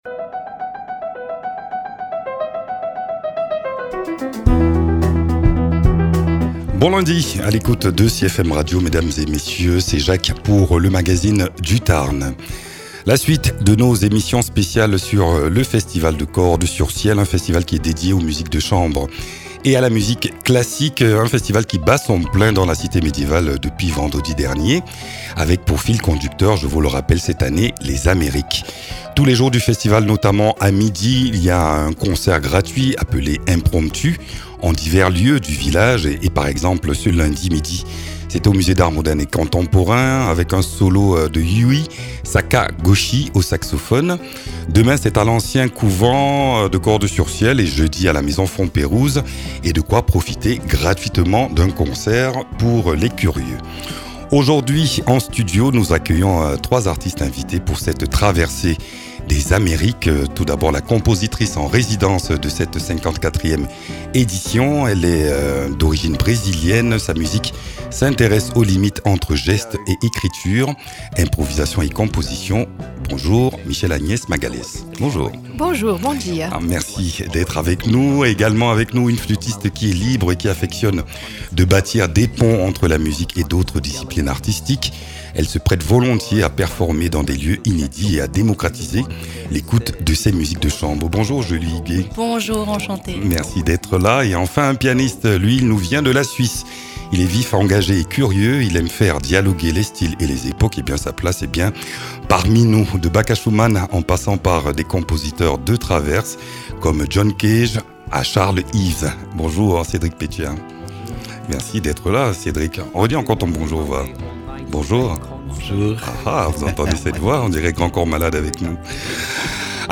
Un échange libre et curieux, à l’image du festival de Cordes sur Ciel, où la musique de chambre sort des sentiers battus pour aller à la rencontre de tous. Écoutez, entre deux notes, la passion qui circule.